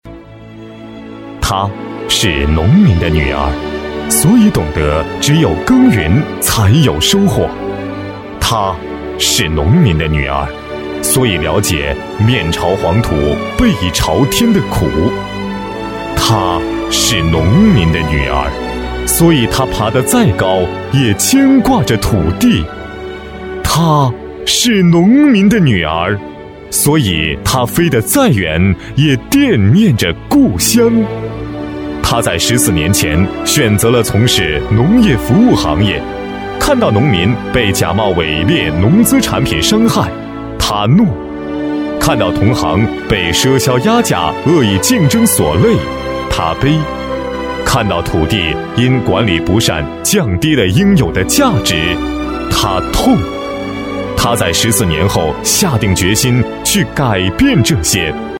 深情缓慢 人物专题
大气稳重男中音，特点：大气透亮，厚重沉稳。